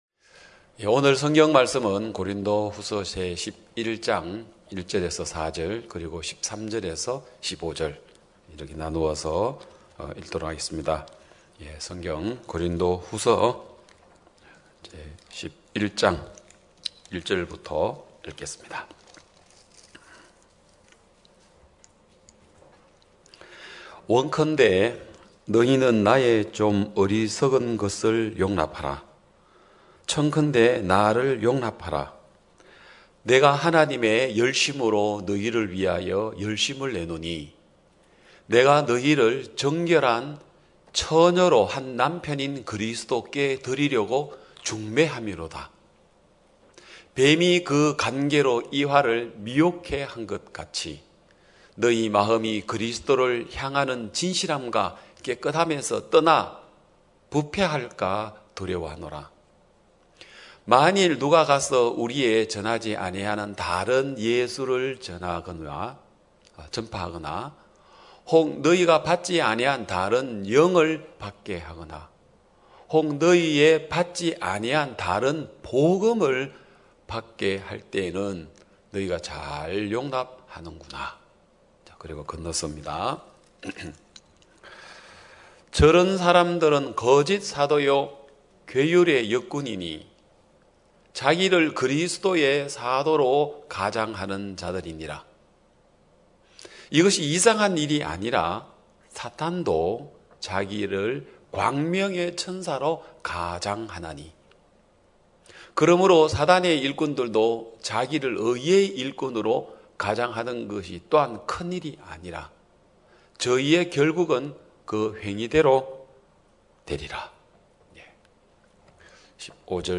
2021년 10월 3일 기쁜소식양천교회 주일오전예배
성도들이 모두 교회에 모여 말씀을 듣는 주일 예배의 설교는, 한 주간 우리 마음을 채웠던 생각을 내려두고 하나님의 말씀으로 가득 채우는 시간입니다.